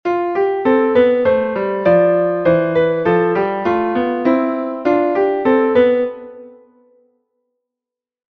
In beiden Arten treffen die Halbtöne nicht zusammen. Nachstehend ein Beispiel von freier Gegenbewegung, die Oktave des Haupttones ist mit der Oktave der Dominante beantwortet: